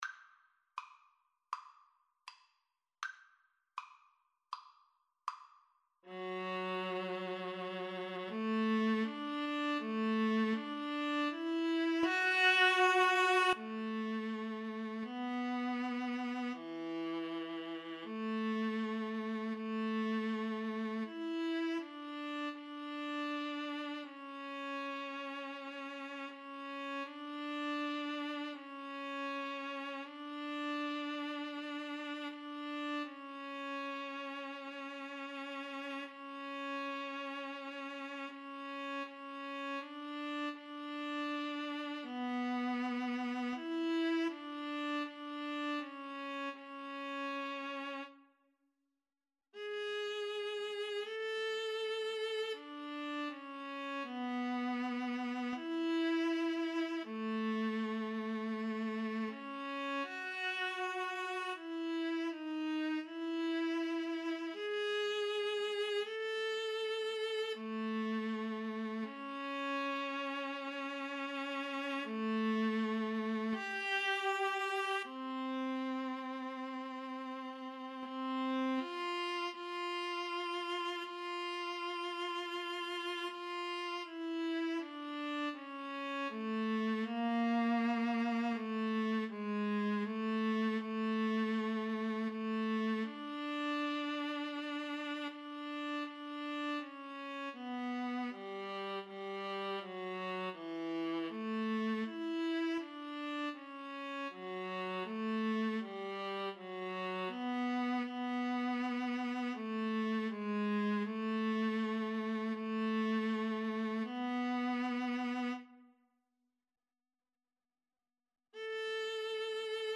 Adagio = c. 40
Classical (View more Classical Viola Duet Music)